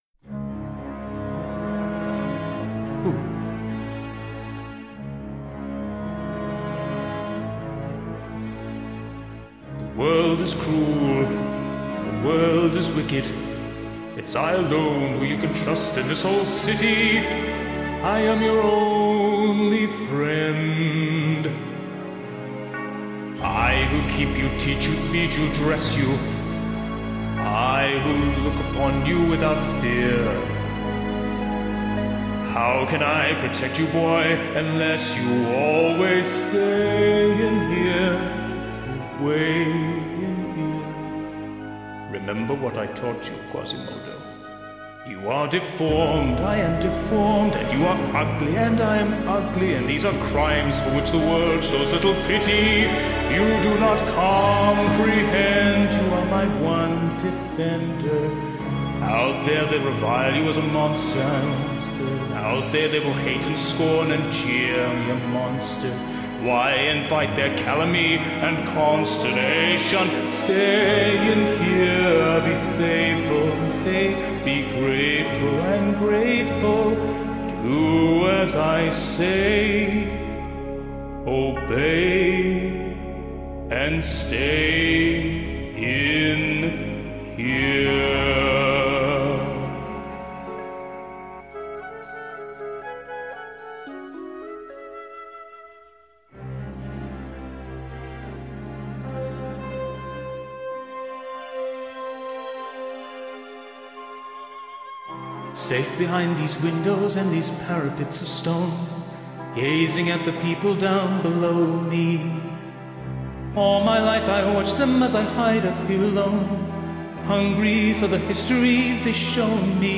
poignant operatic-style hit